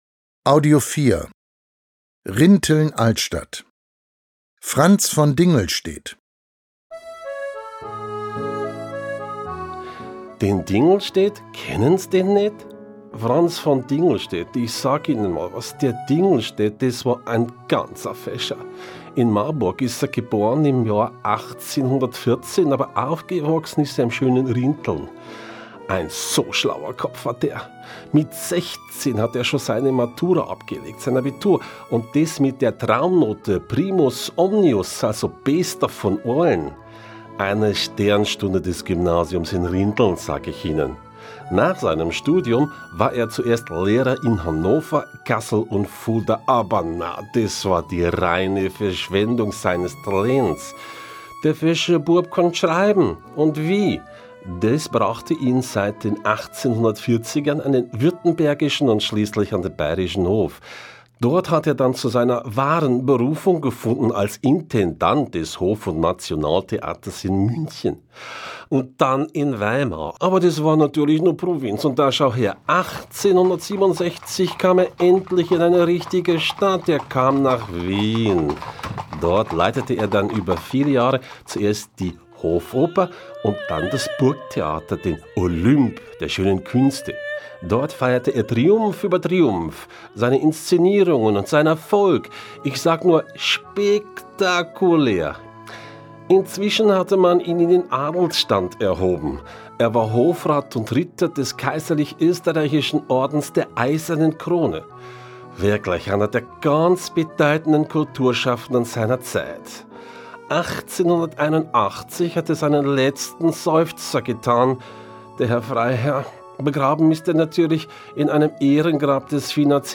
Audioguide Interaktiv
Hören Sie rein in spannende Erzählungen rundum Rinteln und seine Ortsteile. 45 Hörgeschichten führen Sie ausgehend von der Altstadt über das gesamte Stadtgebiet.